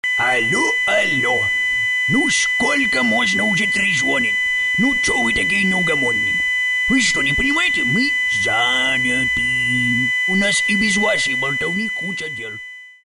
• Пример реалтона содержит искажения (писк).